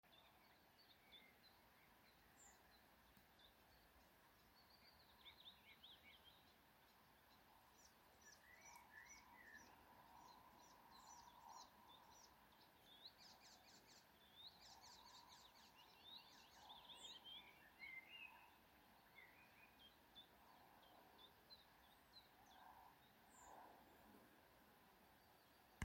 Putni -> Ķauķi ->
Iedzeltenais ķauķis, Hippolais icterina
StatussDzied ligzdošanai piemērotā biotopā (D)